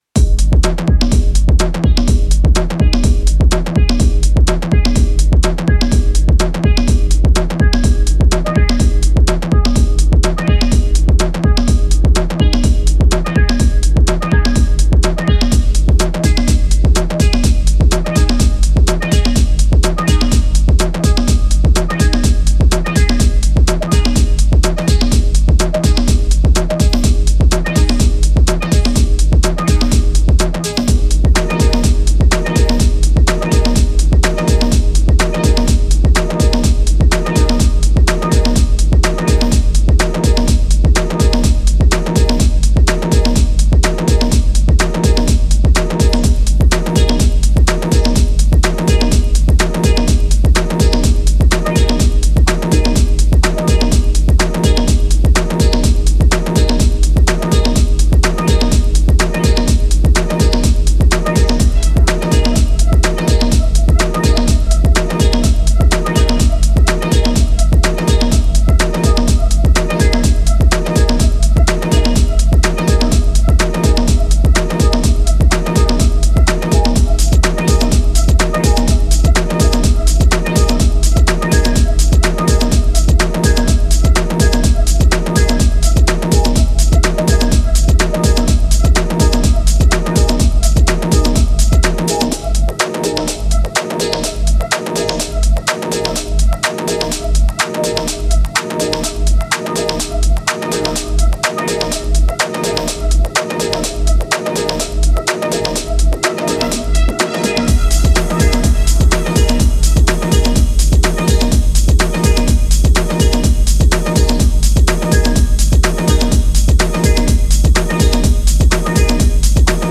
ビリついたパーカッションと不穏なパッドでフロアに足を釘付けする